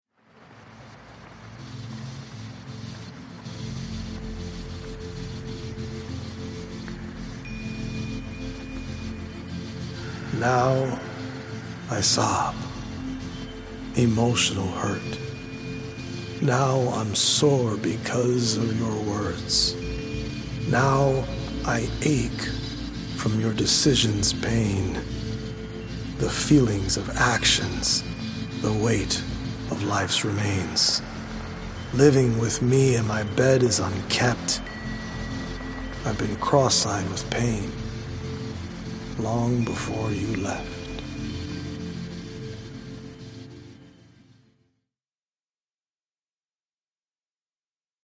healing audio-visual poetic journey
healing Solfeggio frequency music